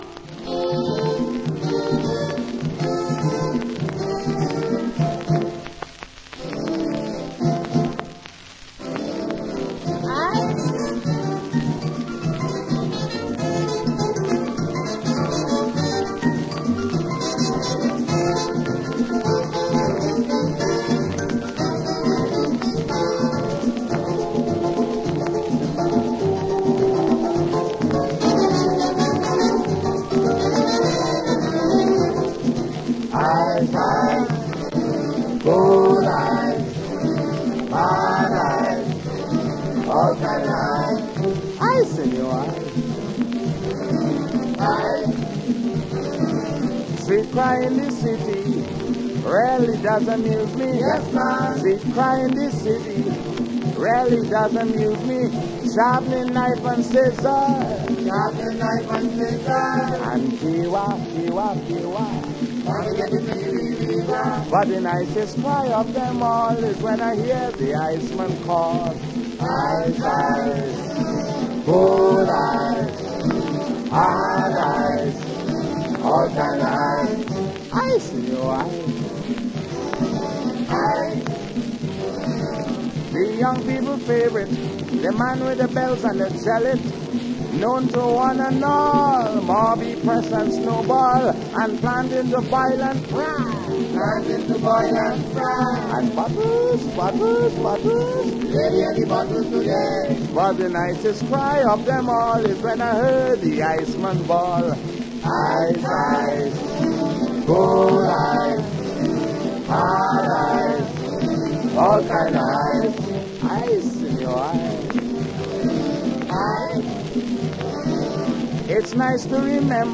an ultra-rare calypso 7″
Vocal harmonies